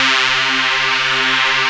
JUNOPULSE2.wav